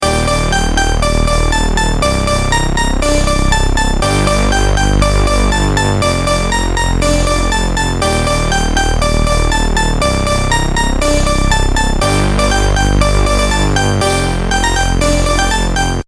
danger-tech_14242.mp3